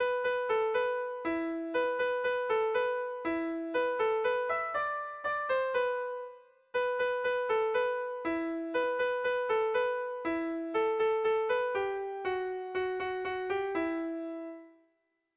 Erromantzea
AB